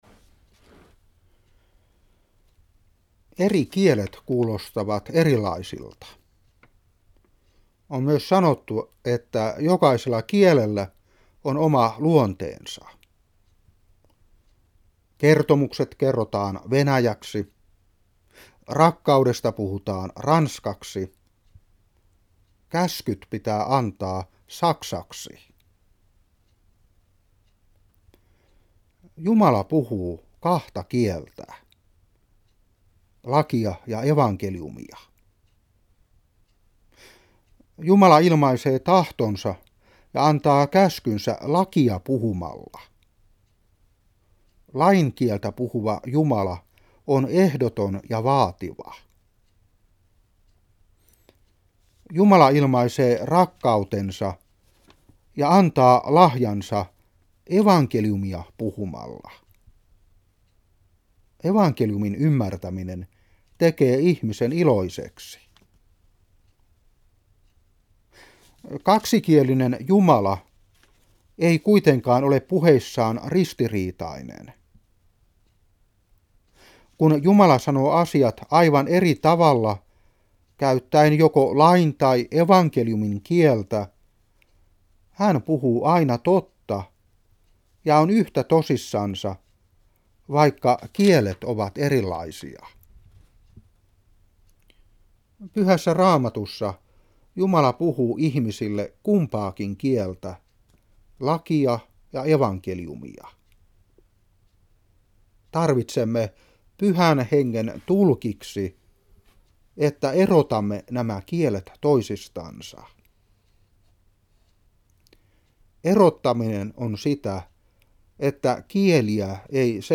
Seurapuhe 2009-9. 1.Joh.4:9-11.